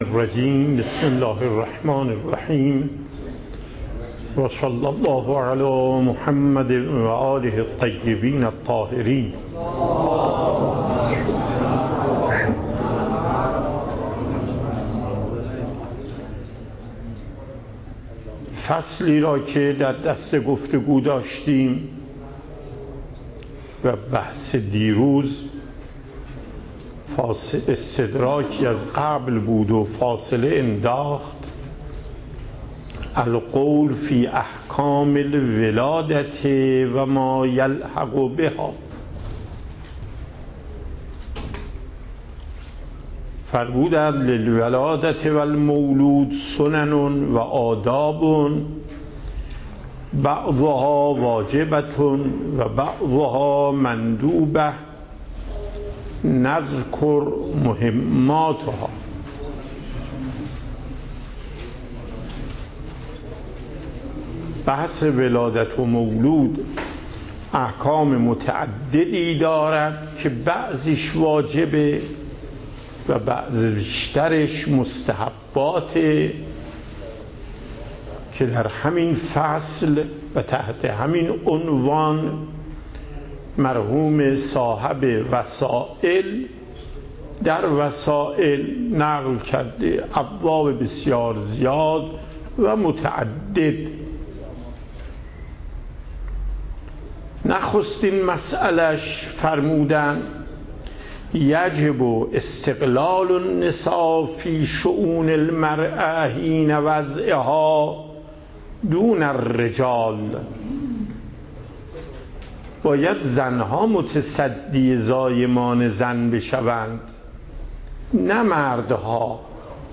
صوت و تقریر درس